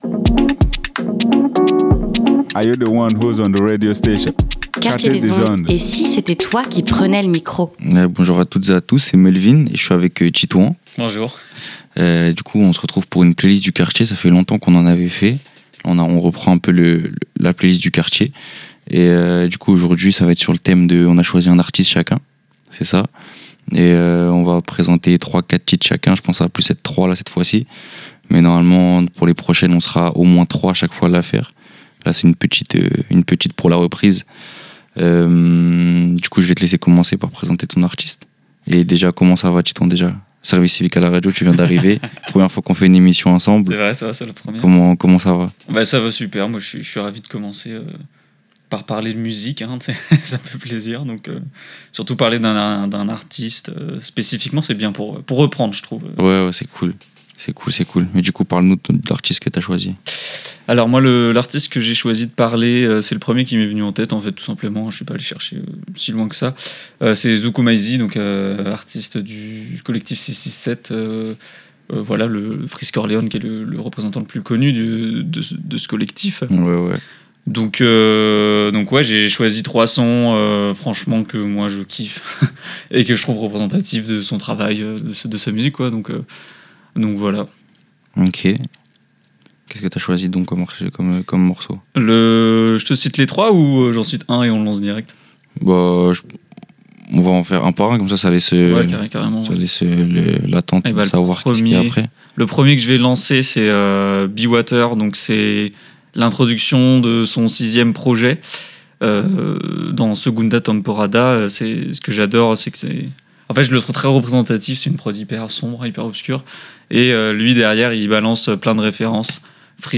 La playlist du quartier, votre rendez-vous musical, chill & relax sur Quartier des Ondes.